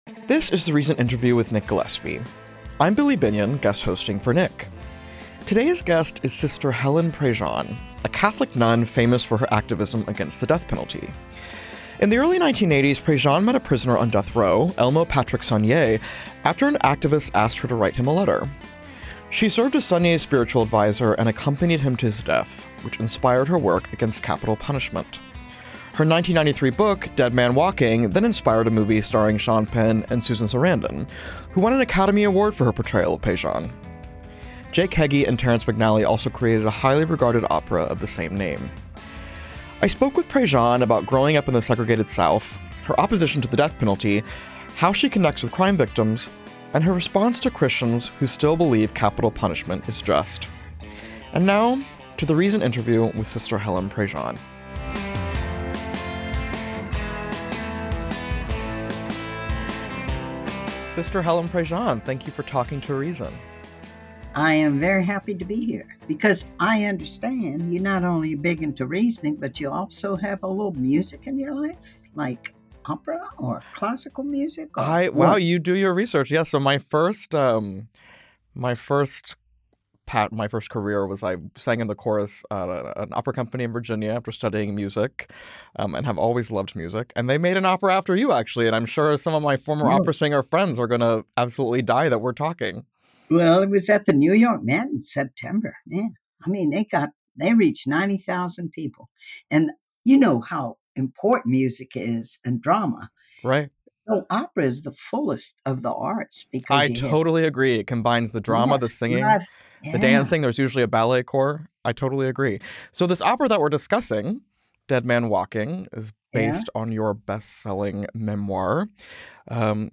Reason’s Nick Gillespie hosts relentlessly interesting interviews with the activists, artists, authors, entrepreneurs, newsmakers, and politicians who are defining the 21st century.